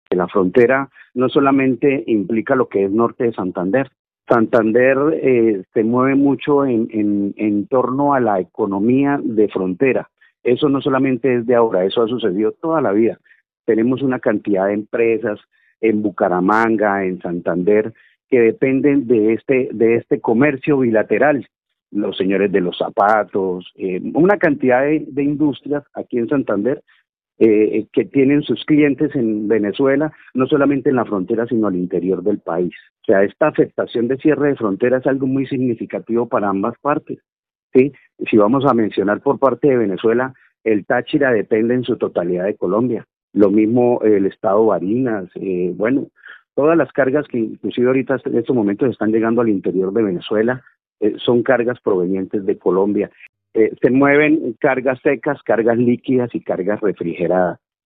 Con este cierre, toda esa cadena productiva empieza a verse afectada”, aseguró en entrevista con Caracol Radio.